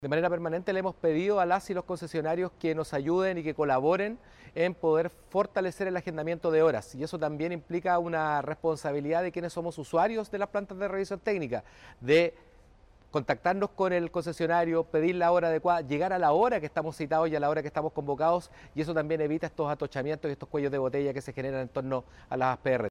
Además, Fierro, se refirió a las situaciones de congestión vehicular o largas filas de espera.